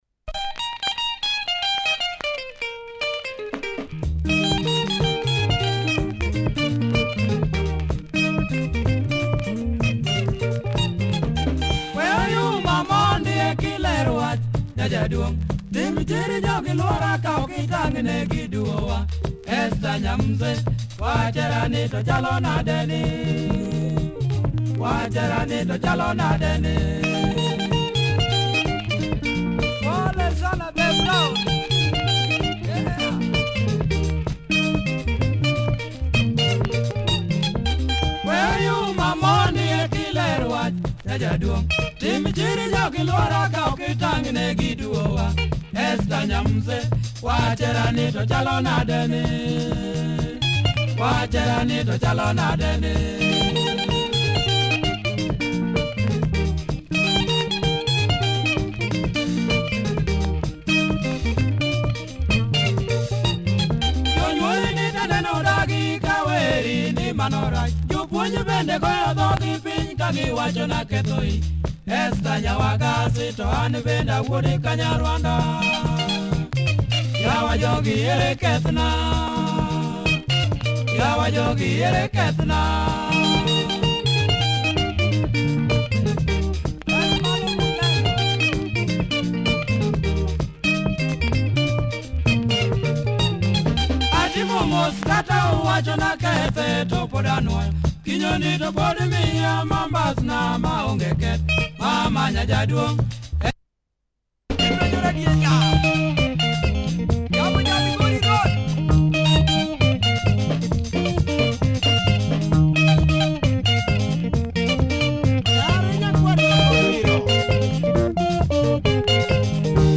Great luo benga